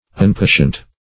unpatient - definition of unpatient - synonyms, pronunciation, spelling from Free Dictionary
unpatient - definition of unpatient - synonyms, pronunciation, spelling from Free Dictionary Search Result for " unpatient" : The Collaborative International Dictionary of English v.0.48: Unpatient \Un*pa"tient\, a. Impatient.